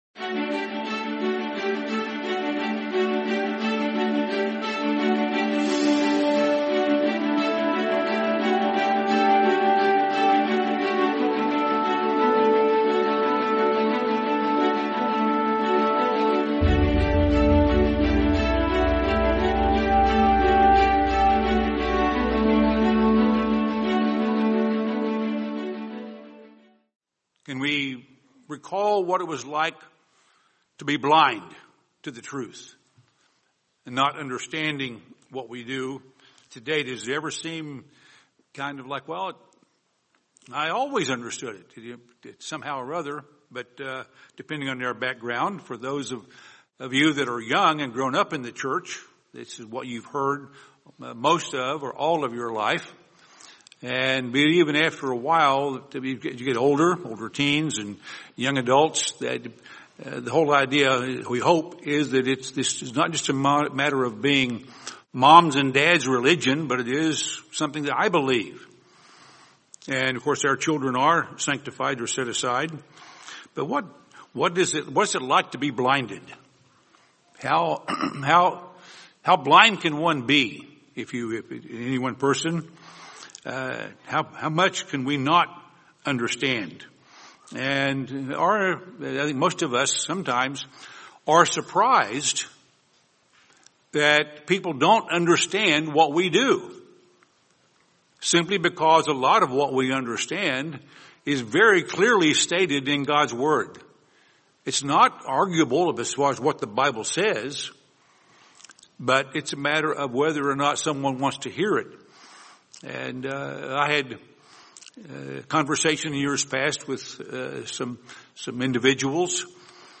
How and Why God Calls His Servants | Sermon | LCG Members